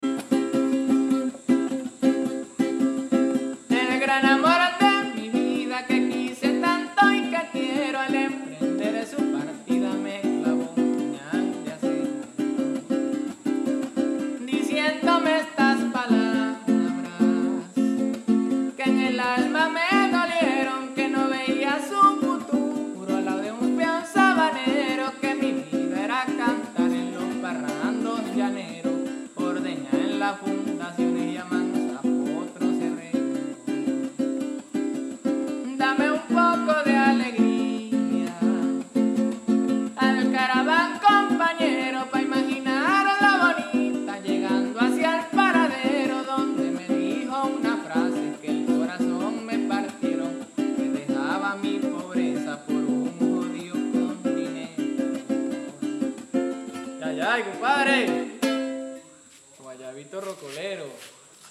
Pasajito rocolero 🤠.